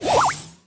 item_swirl_01.wav